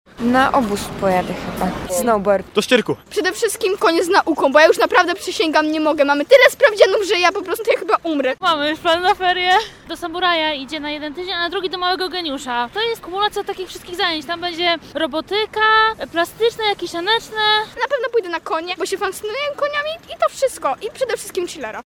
O to pytamy w sondzie z mikrofonem RR.